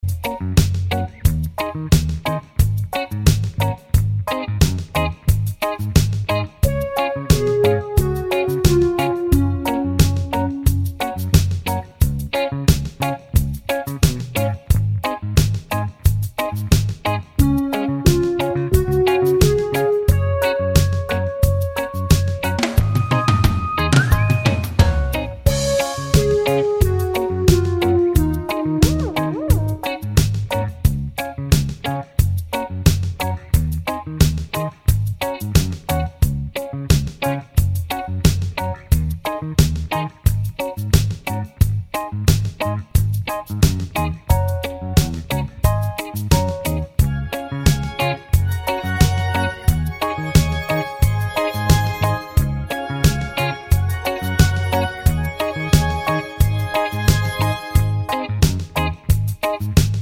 no Backing Vocals Reggae 3:08 Buy £1.50